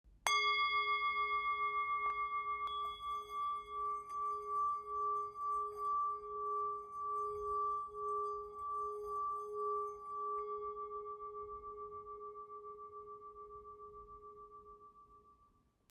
Tepaná tibetská mísa Non o hmotnosti 444 g a malá palička s kůží.
Součástí tibetské mísy Non je i palička na hraní.
Zvuk tibetské mísy Non si můžete poslechnout
tibetska_misa_m58.mp3